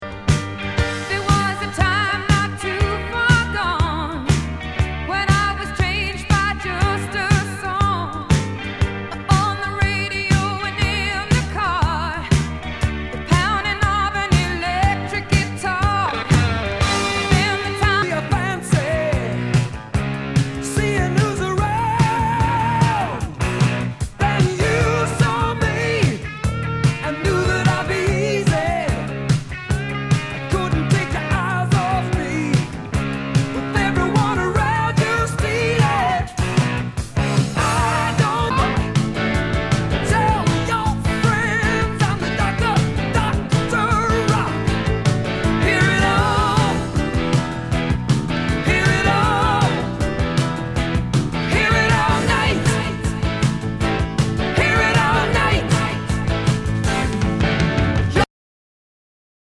[ Genre ] ROCK/POPS/INDIE
ナイス??！Pop Rock！